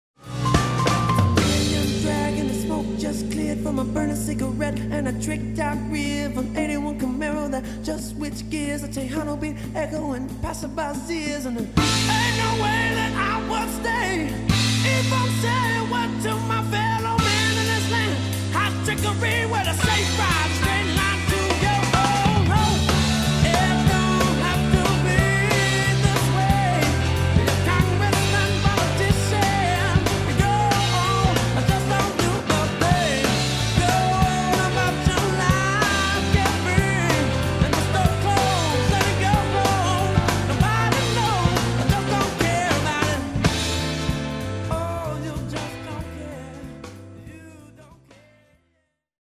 This is a great studio in theTexas Hill Country.